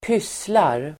Uttal: [²p'ys:lar]